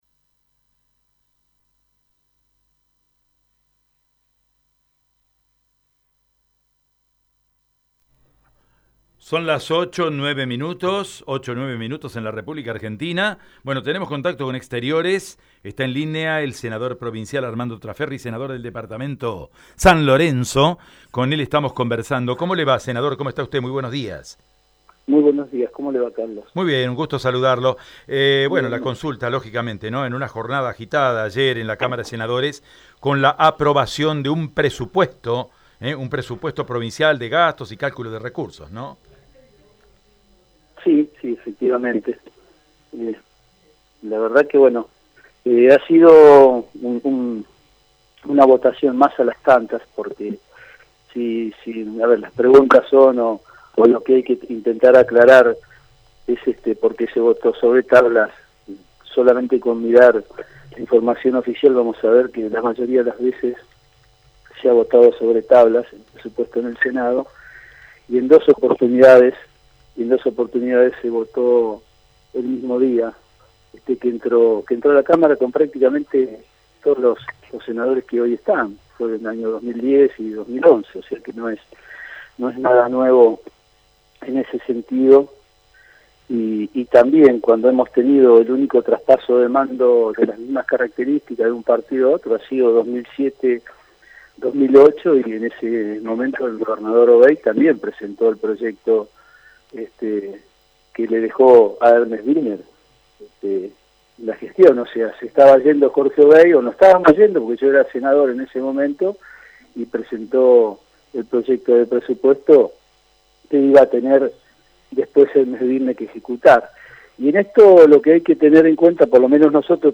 En dialogo con Radio EME, el senador provincial por el Partido Justicialista habló sobre la polémica aprobación del presupuesto provincial 2020.
EM-Armando-Traferri-–-Senador-Dpto-San-Lorenzo.mp3